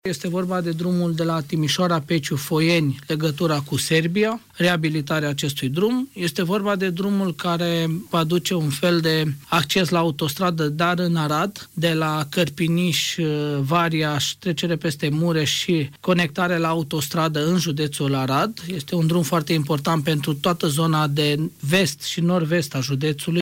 Președintele instituţiei, Călin Dobra, spune că petru aceste drumuri se depun cereri de finanțare europeană, la fel ca în cazul altor două drumuri din județ.